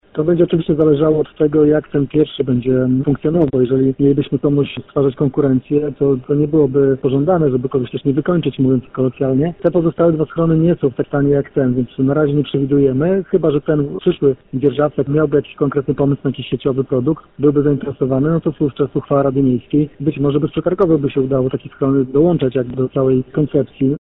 Burmistrz nie wyklucza, że pozostałe schrony mogłyby zostać w przyszłości wydzierżawione, jeżeli wszystkie trzy stanowiłyby integralną atrakcję.